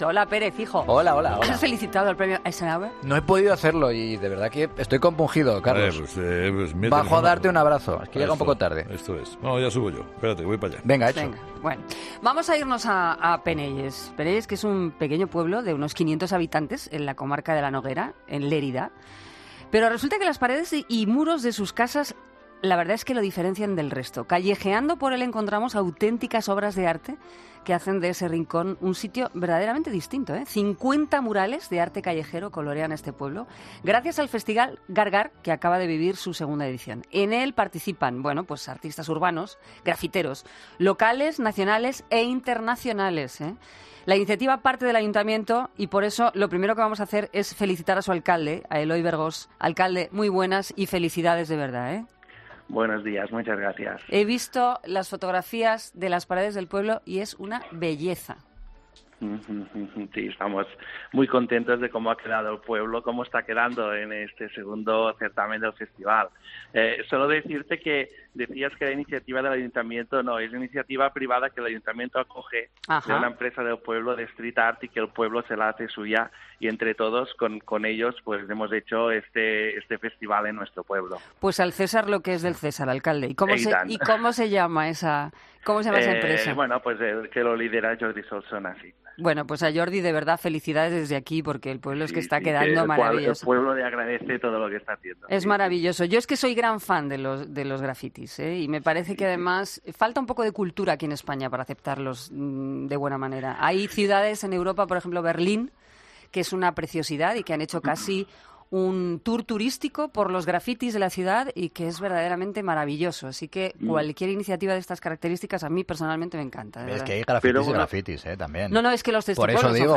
Eloi Bergós, alcalde de Penelles: "Estos murales son una forma de luchar contra la extinción"